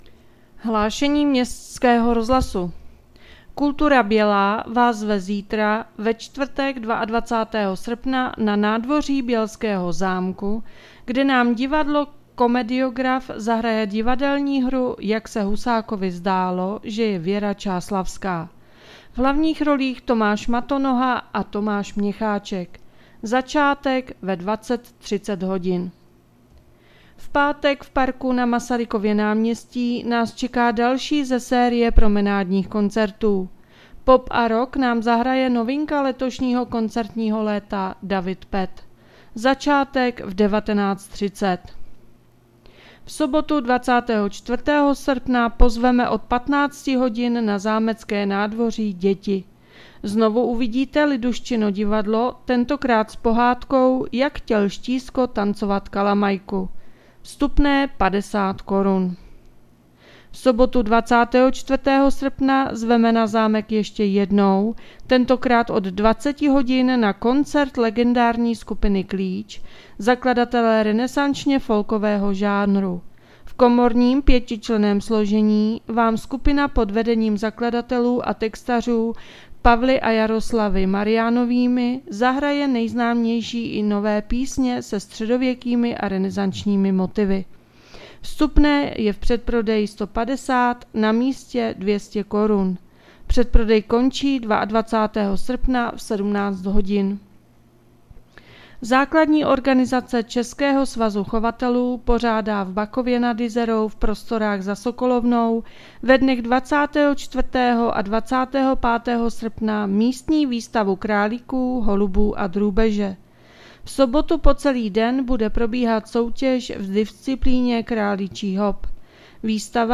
Hlášení městského rozhlasu 21.8.2024